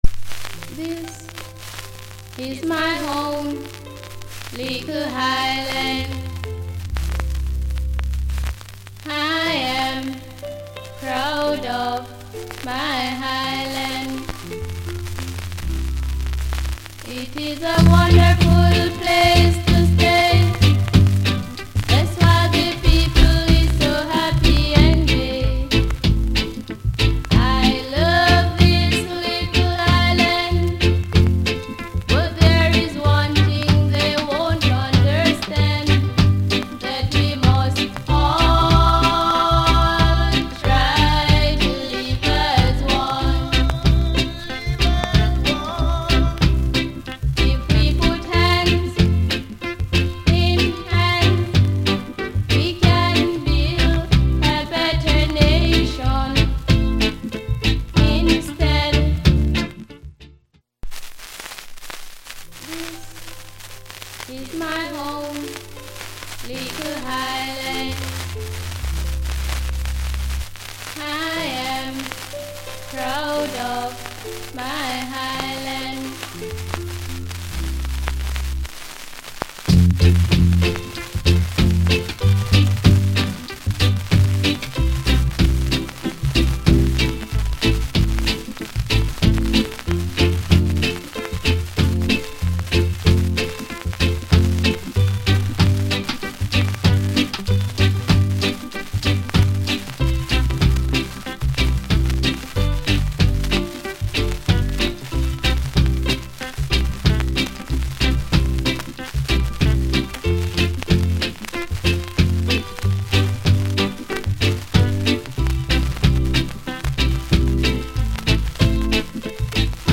* Rare Female